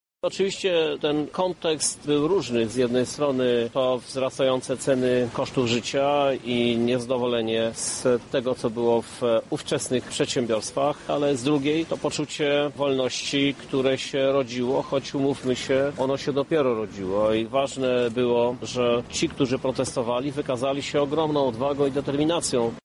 Bez protestów lipcowych nie byłoby być może późniejszych wydarzeń sierpniowych– mówi prezydent miasta Lublin Krzysztof Żuk